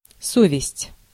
Ääntäminen
IPA : /ˈkɑn.ʃəns/